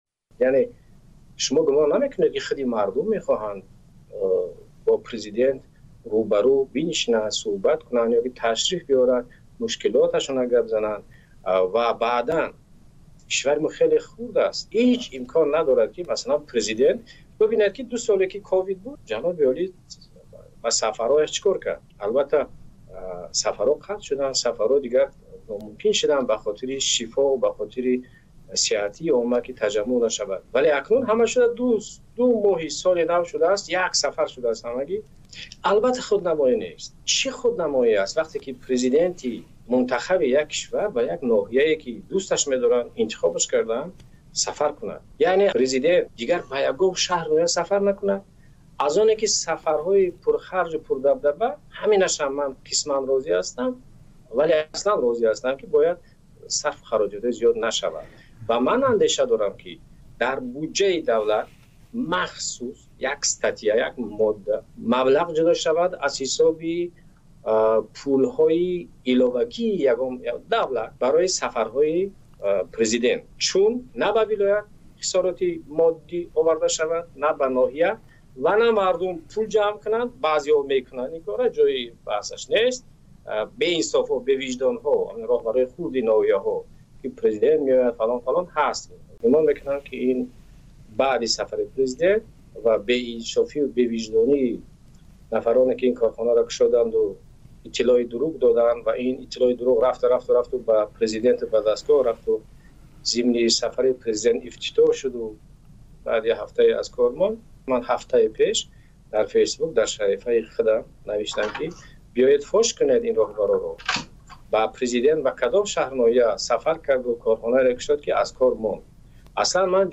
Шунавандаи назари коршиноси тоҷик дар бораи паёмадҳои сафарҳои устонии раиси ҷумҳӯри Тоҷикистон бошед.